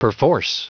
Prononciation du mot perforce en anglais (fichier audio)
Prononciation du mot : perforce